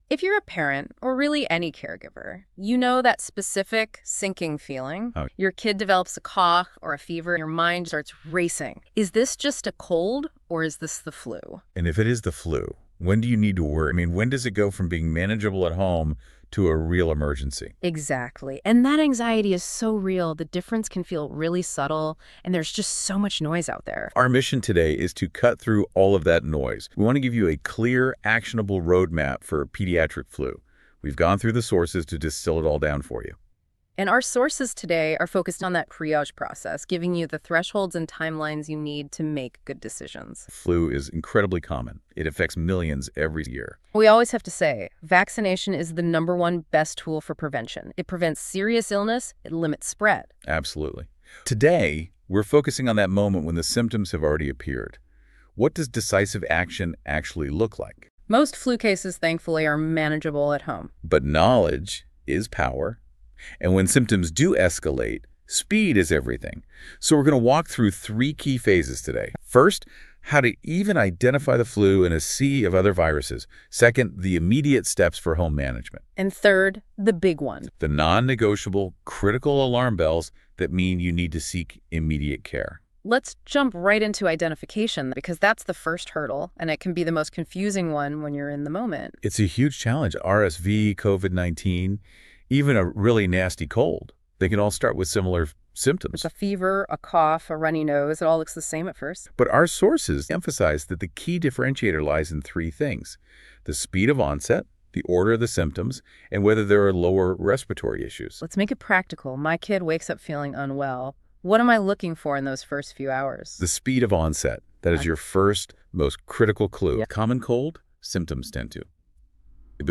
Listen to a discussion about when to take your child to the ER for the flu When to take your child to the ER for the flu If your child exhibits serious flu symptoms, you should take them to the ER for evaluation.